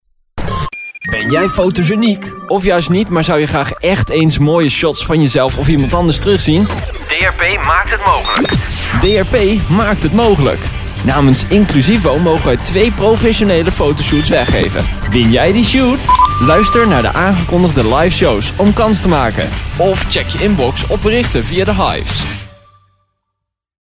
Dat houdt in dat ik dus al enkele maanden heel wat reclametekstjes en ‘jingles’ inspreek voor hem.
Promo Fotoshoot